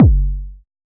RDM_TapeA_SY1-Kick03.wav